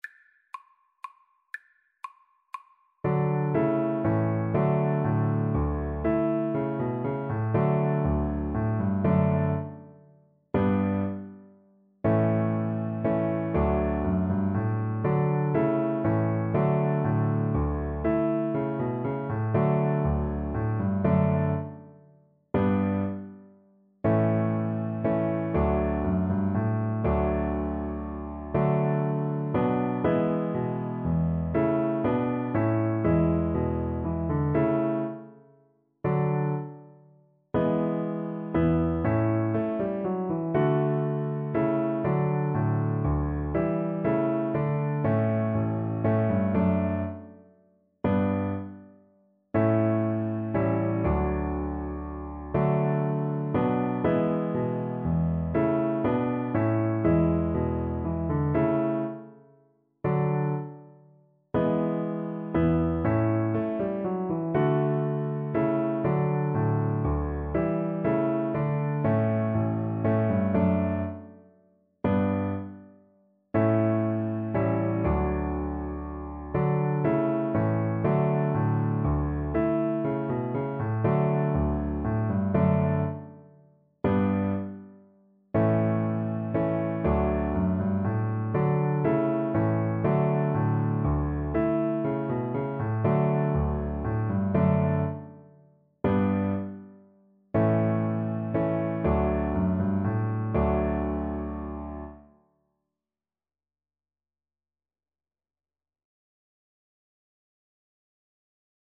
3/4 (View more 3/4 Music)
~ = 100 Allegretto =120
Classical (View more Classical Cello Music)